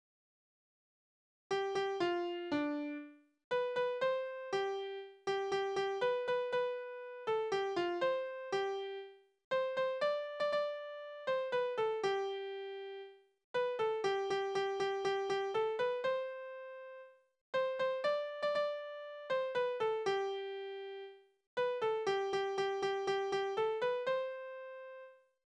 Soldatenlieder:
Tonart: C-Dur
Taktart: C (4/4)
Tonumfang: Oktave
Besetzung: vokal